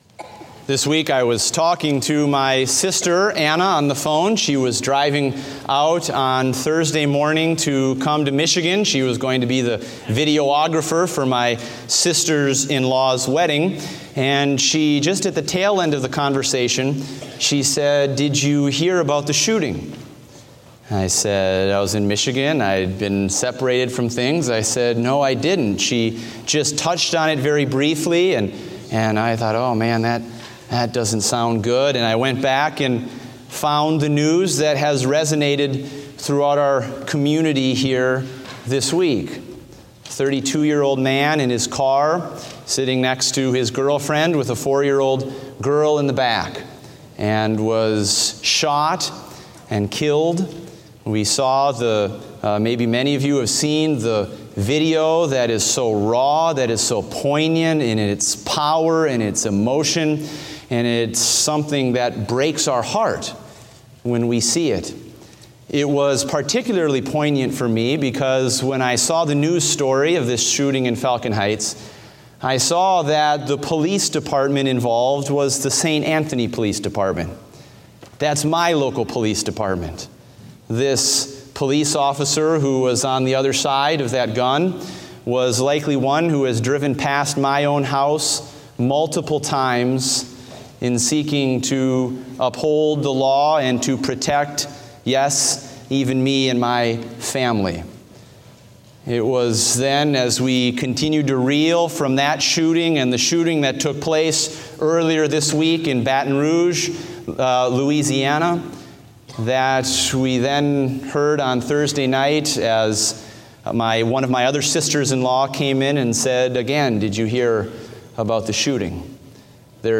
Date: July 10, 2016 (Morning Service)